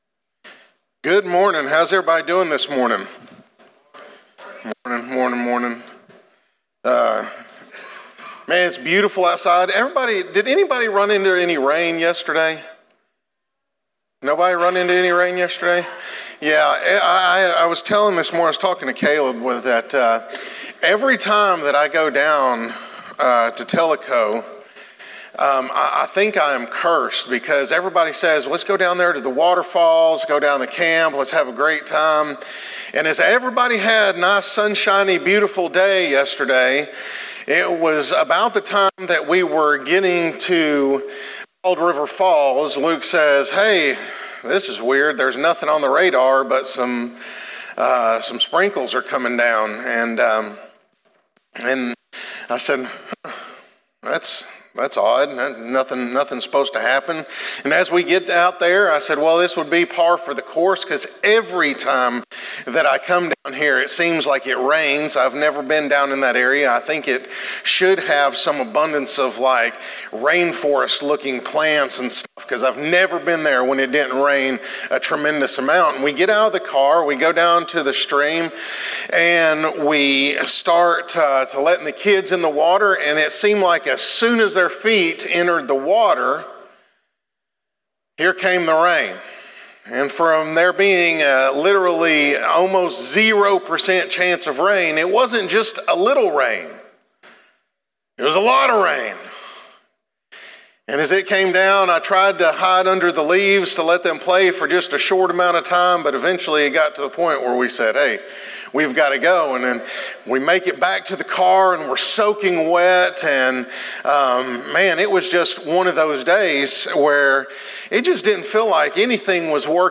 sermon.cfm